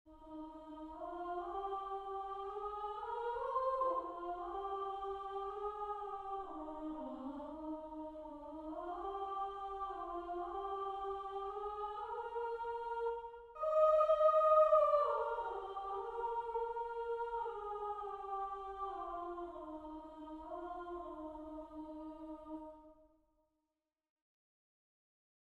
Number of voices: 4vv
Unknown Genre: Secular, Canon
Language: English Instruments: A cappella
First published: 2025 Description: A four voice round.